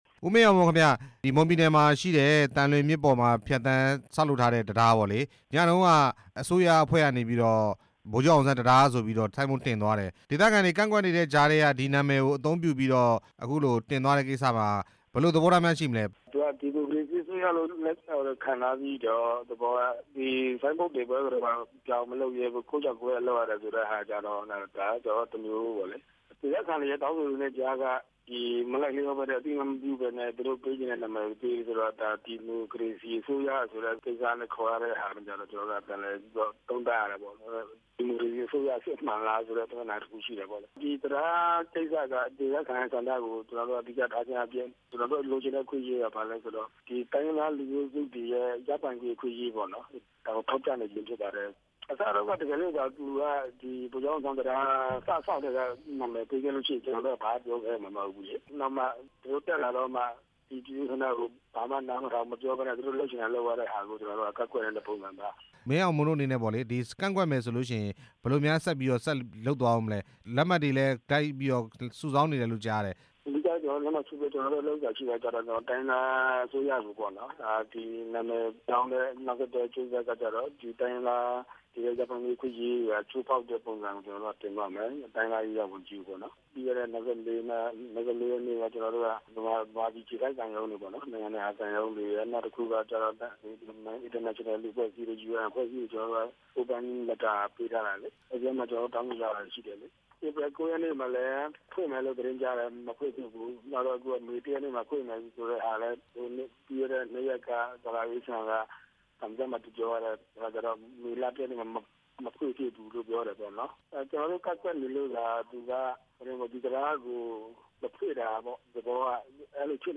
ဗိုလ်ချုပ်အောင်ဆန်းတံတားနဲ့ ဒေသခံ သဘောထားမေးမြန်း ချက်